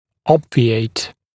[‘ɔbvɪeɪt][‘обвиэйт]избегать, устранять, избавляться